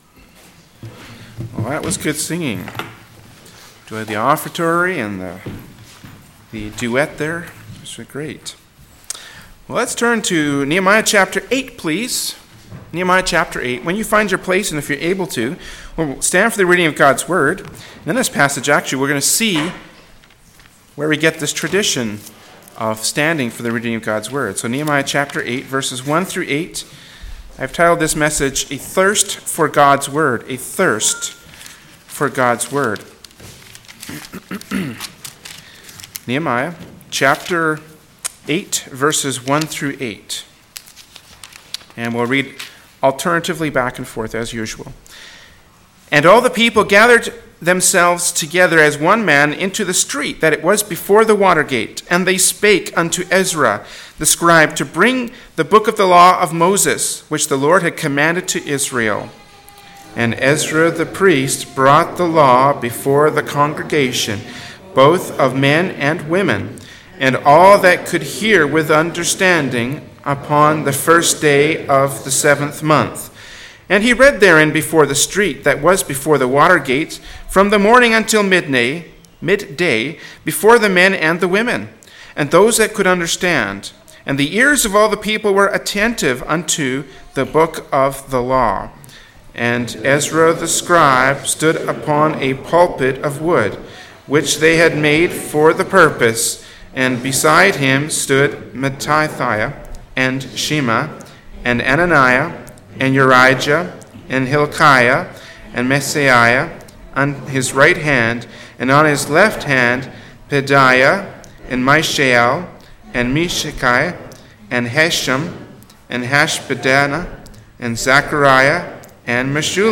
“A Thirst for God’s Word” from Sunday Morning Worship Service by Berean Baptist Church.